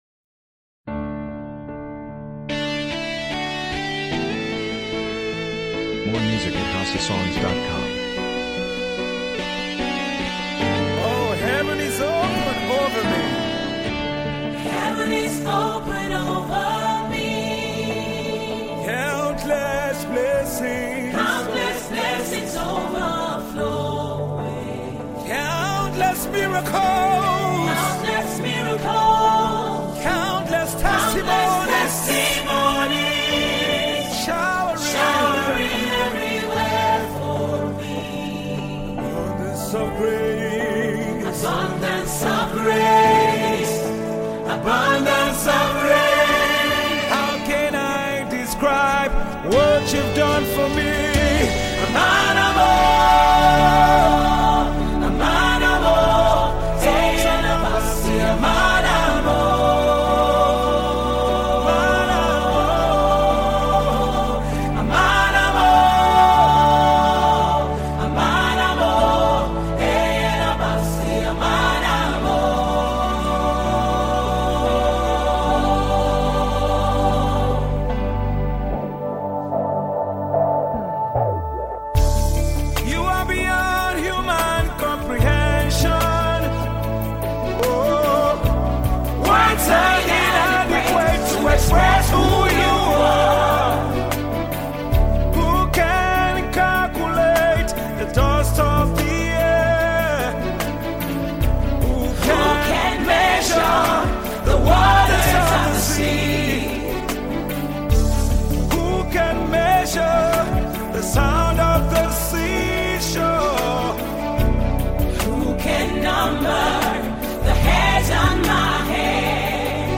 Your desired Tiv song